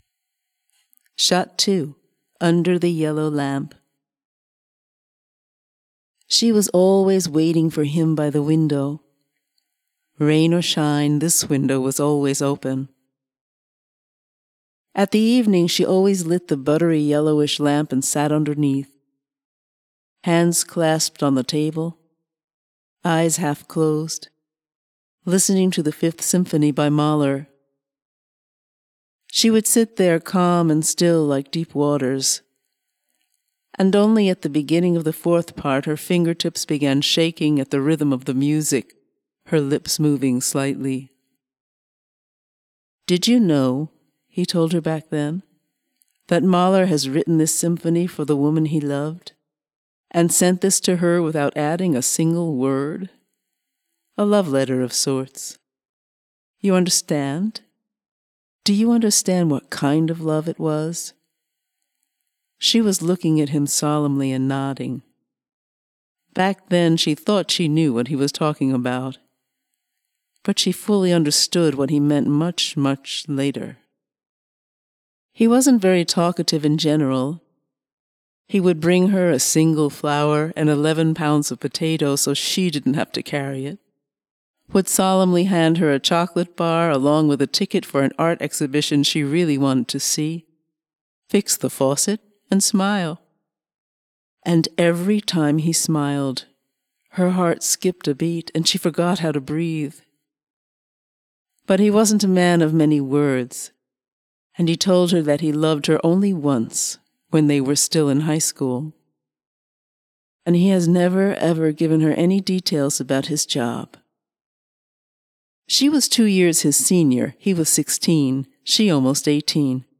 Touchful short audio story about young people in Israel. Love and care, war and danger, death and faith.